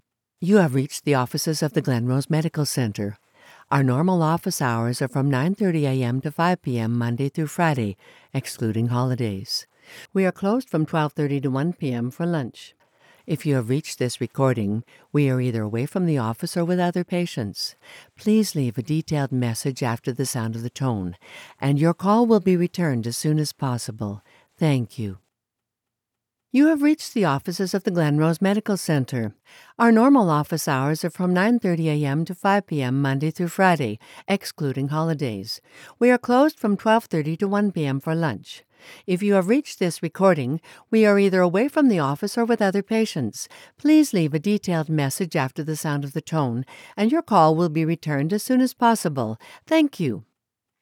IVR Sample 2 Takes
English - USA and Canada
Middle Aged
IVR Sample 2 takes.mp3